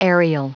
Prononciation du mot aerial en anglais (fichier audio)
Prononciation du mot : aerial